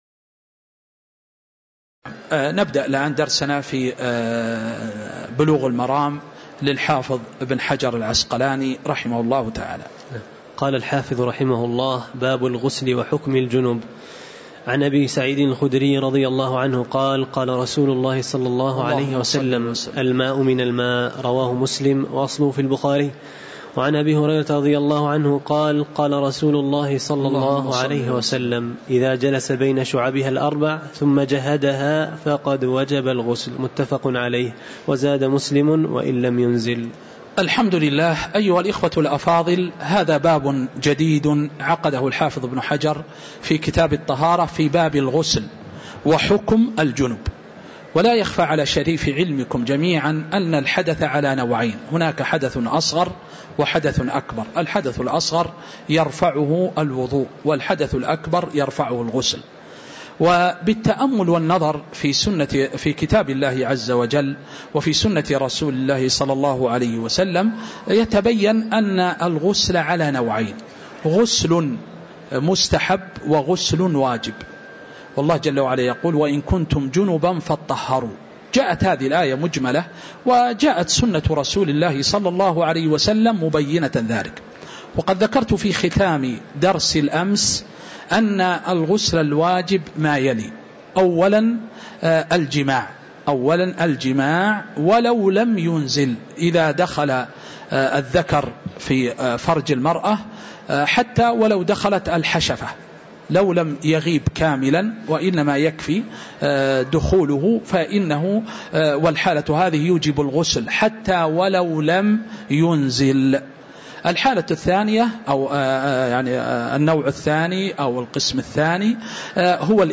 تاريخ النشر ١٥ ذو الحجة ١٤٤٤ هـ المكان: المسجد النبوي الشيخ